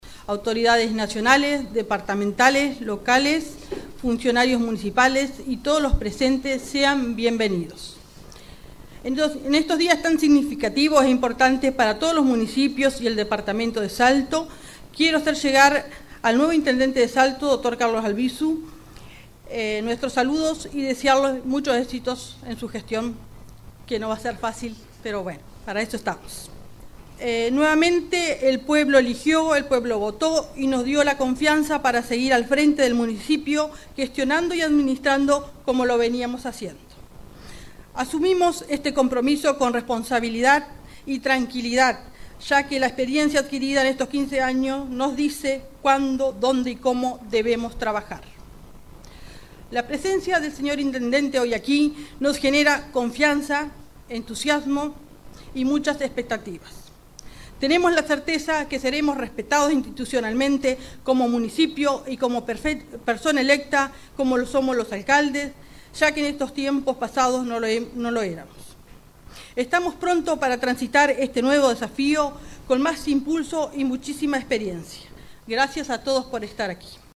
Por su parte, la nueva alcaldesa, Sandra Toncobitz, agradeció el respaldo de la ciudadanía y destacó la relevancia del momento, no solo para el Municipio de San Antonio, sino para todo el departamento de Salto. En su discurso, saludó al nuevo intendente departamental, Dr. Carlos Albisu, a quien le deseó una gestión exitosa.